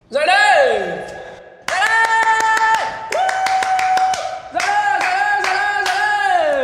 Thể loại: Nhạc chuông